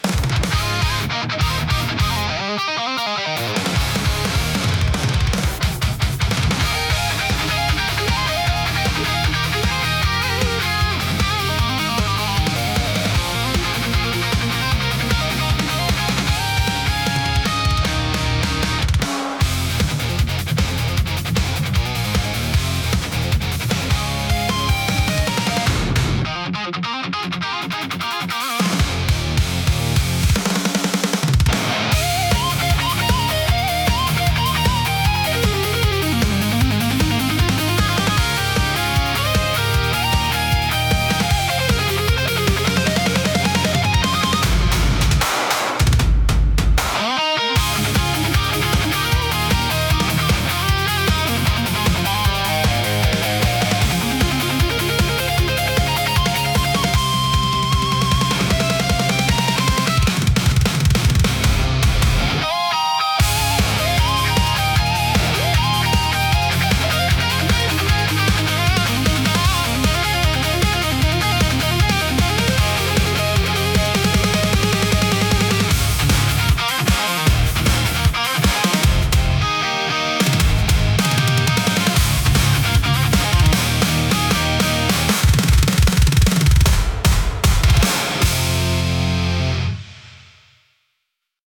イメージ：インスト,パワーロック,オルタネイティブ・ロック
インストゥルメンタル（instrumental）